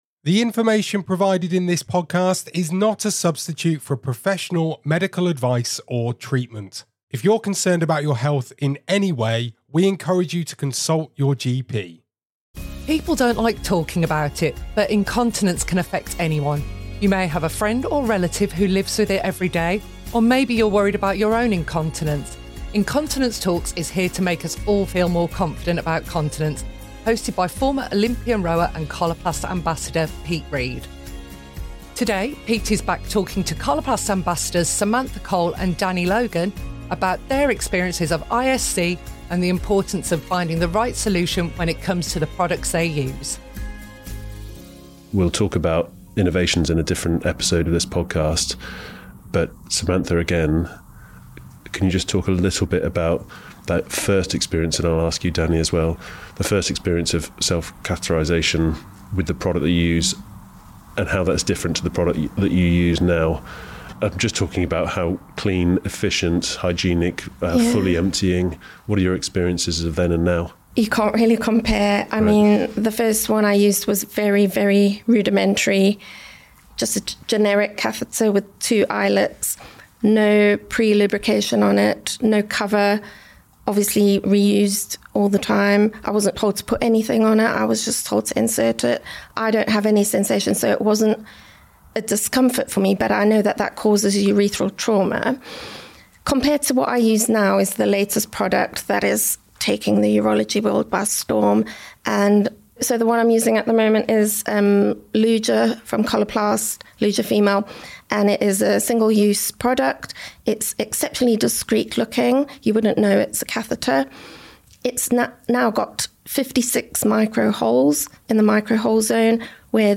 Our trio talk about the products they use and the importance of finding one that suits you. They also share how they bring up the subject of ISC with their family and friends.